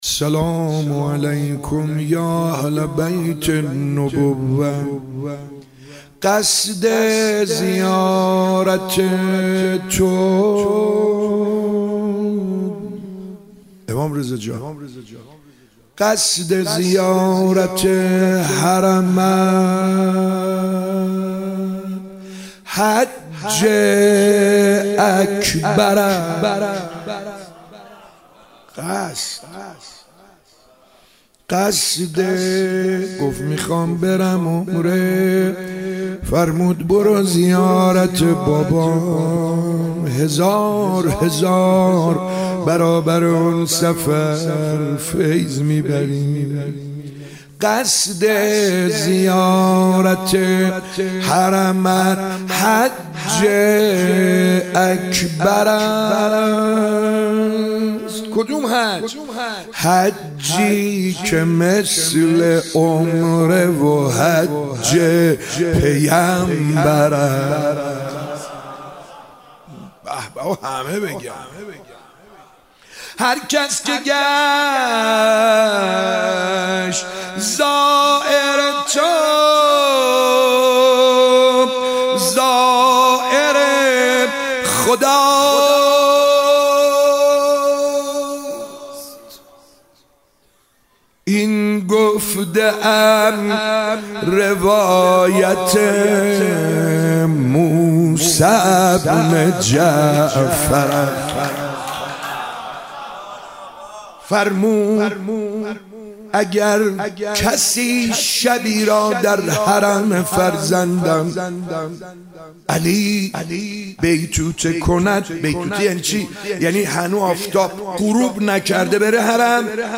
مداحی سعید حدادیان در شب شهادت امام رضا (ع) - تسنیم
فایل صوتی مداحی سعید حدادیان در شب شهادت حضرت رضا (ع) منتشر شد.
سعید حدادیان مداح اهل‌بیت (ع) شب گذشته در مهدیه امام حسن مجتبی علیه‌السلام به مدیحه‌سرایی درباره وجود مقدس امام رضا علیه‌السلام پرداخت که صوت آن را در ادامه می‌شنوید.